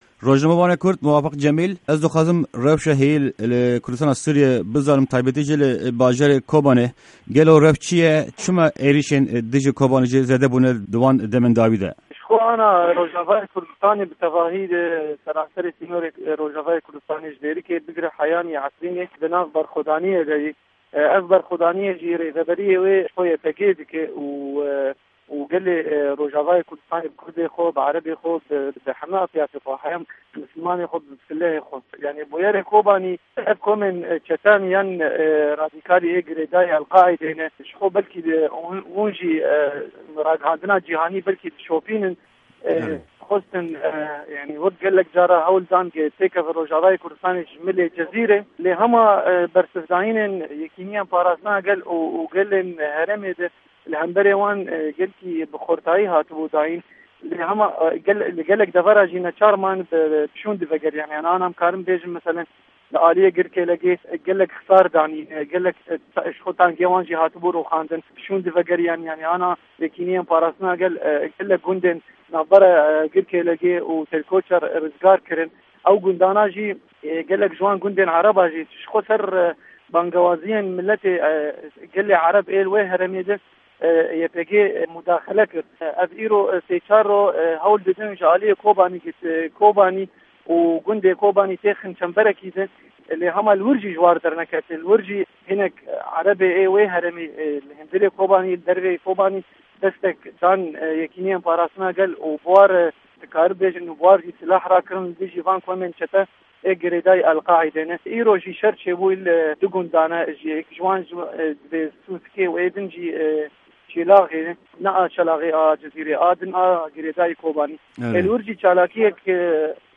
Di hevpeyvîna Pişka Kurdî ya Dengê Amerîka de rojnamevan